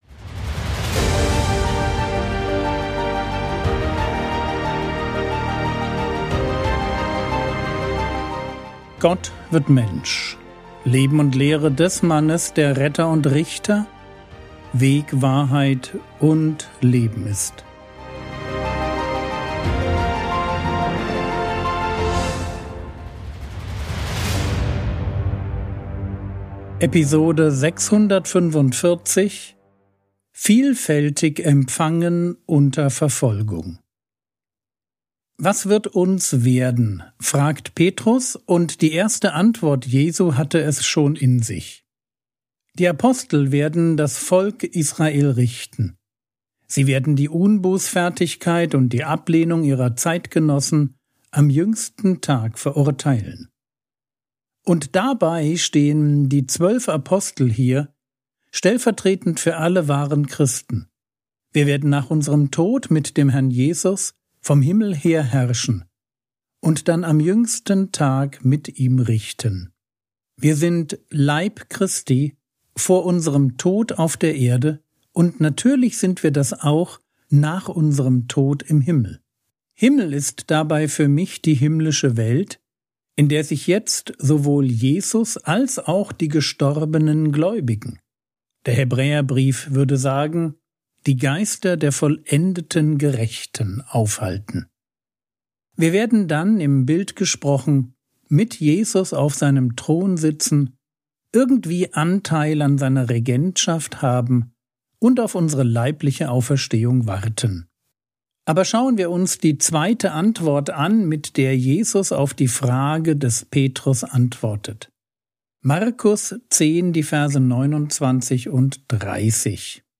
Episode 645 | Jesu Leben und Lehre ~ Frogwords Mini-Predigt Podcast